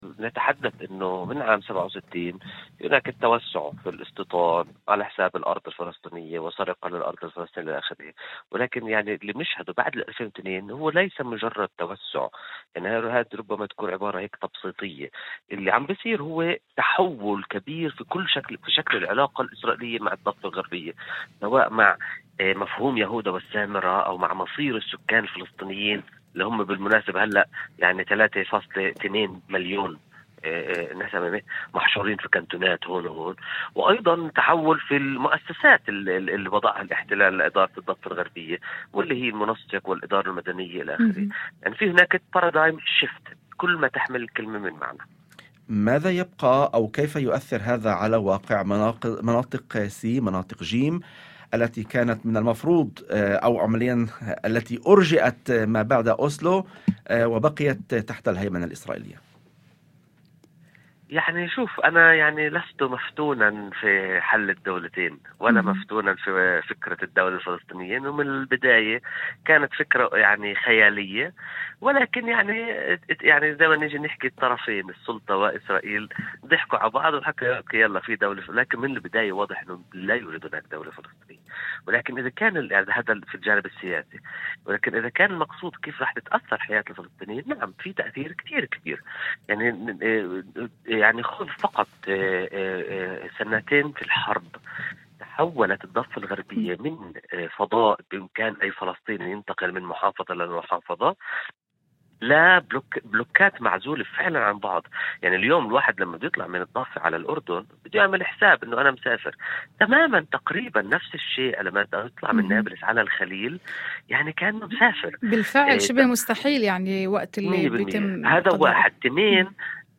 وفي مداخلة هاتفية ضمن برنامج "يوم جديد"، على إذاعة الشمس، أشار إلى أن إسرائيل، منذ عام 1967، تعاملت مع الضفة باعتبارها مسألة أراضٍ وملكية أكثر من كونها وحدة جغرافية متكاملة، لكن المرحلة الحالية تمثل انتقالاً من إدارة الصراع مع وجود فلسطيني دائم إلى محاولة حسمه وإعادة تشكيل الواقع الديمغرافي والسياسي.